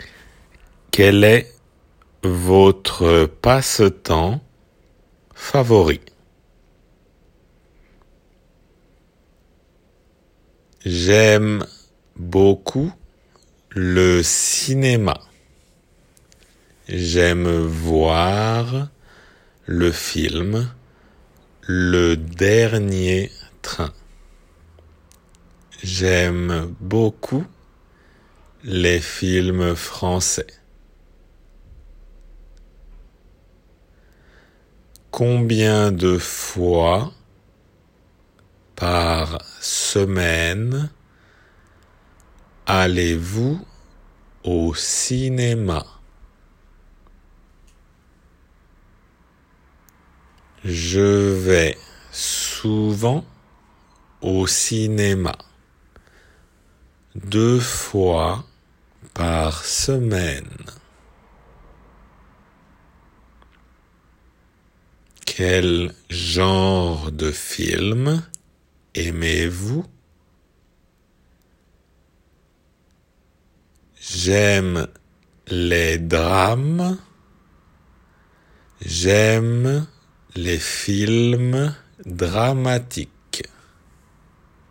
少しゆっくり目の音声です。